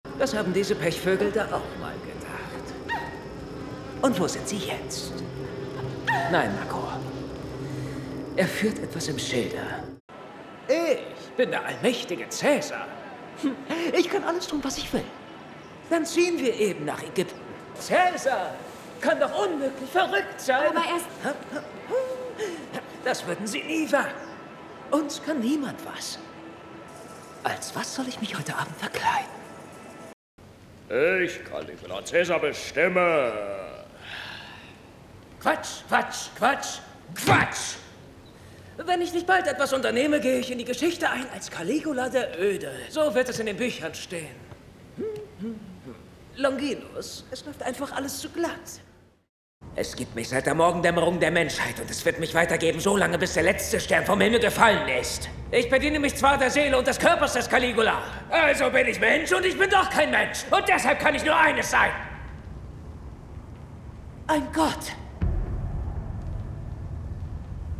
Klar, markant, frisch, wandelbar, witzig, sinnlich.